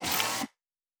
pgs/Assets/Audio/Sci-Fi Sounds/Mechanical/Servo Small 7_1.wav at 7452e70b8c5ad2f7daae623e1a952eb18c9caab4
Servo Small 7_1.wav